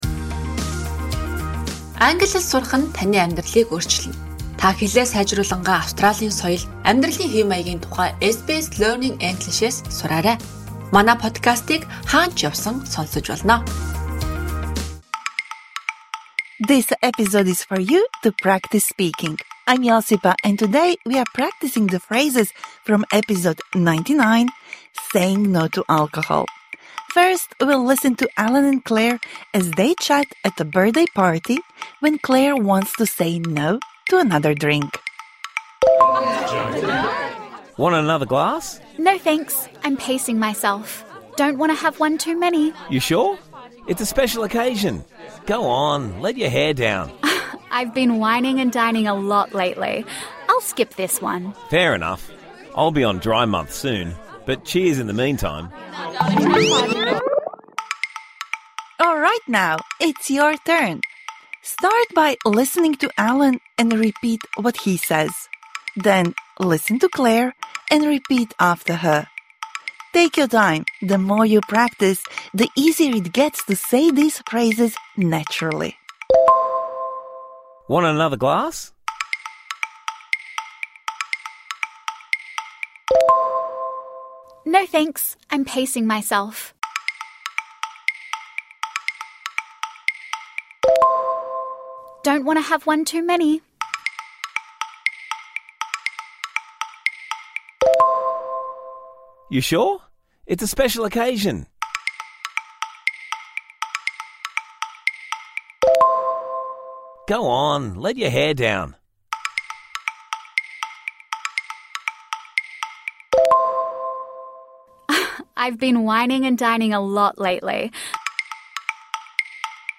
This bonus episode provides interactive speaking practice for the words and phrases you learnt in #99 Saying 'No' to alcohol.